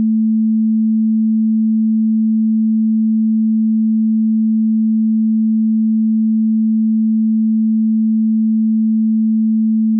front_sin220_ambiX.wav